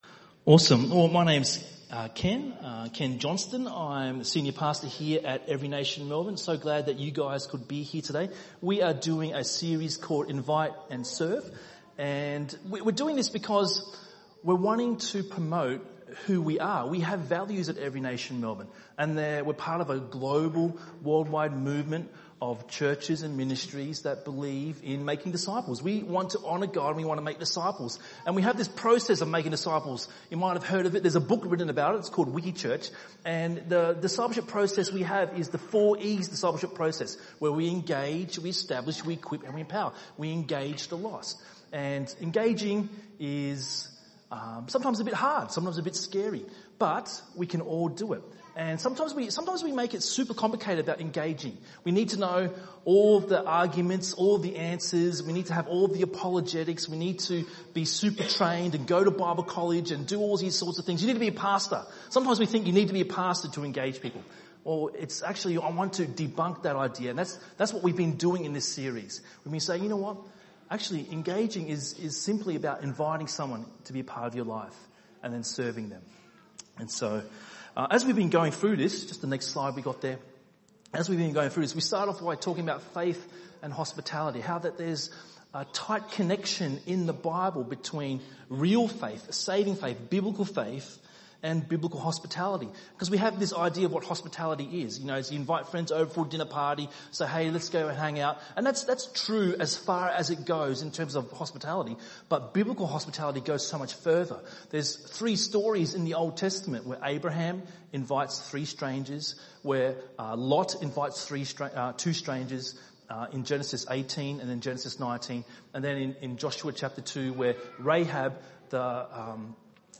by enmelbourne | Nov 11, 2019 | ENM Sermon